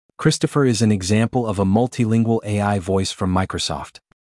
Because AI voice models are developed directly from human speech samples, the audio created from the start is more natural sounding as it mimics qualities of the original.